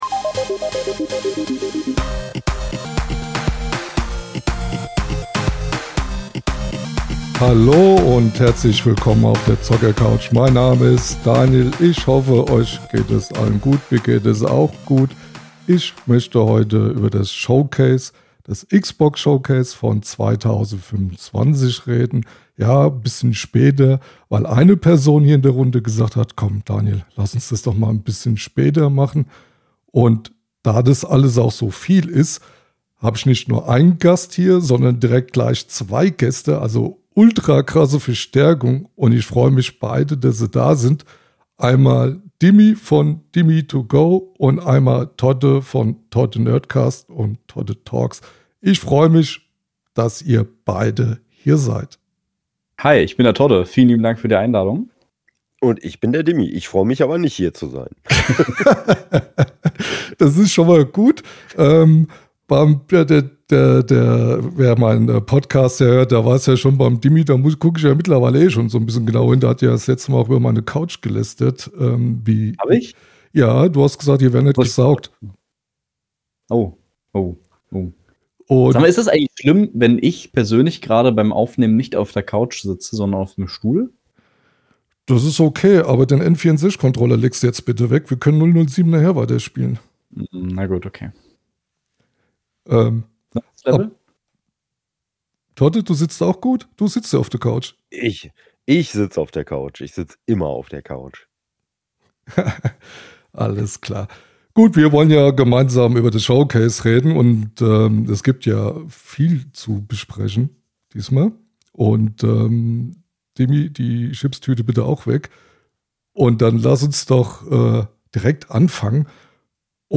Freut euch auf ehrliche Gespräche, unterschiedliche Perspektiven und jede Menge Xbox-Leidenschaft!